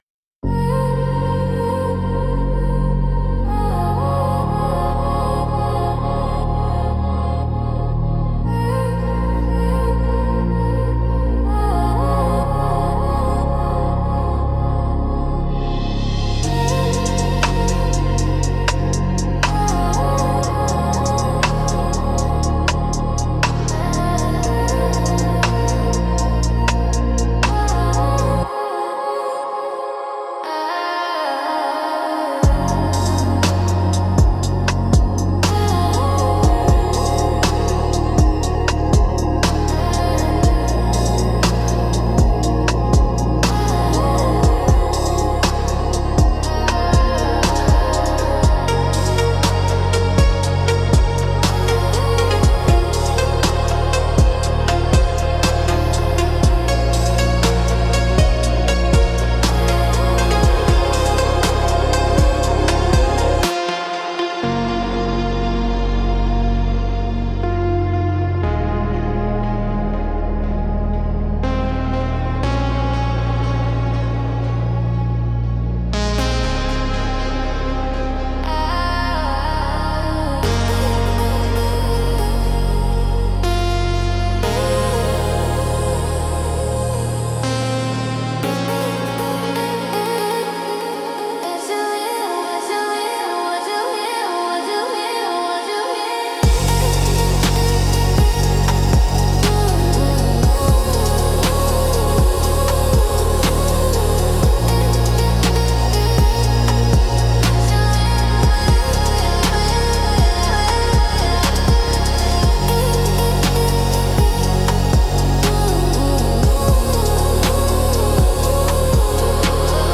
Instrumental - Warm Glow, Cold World 3.11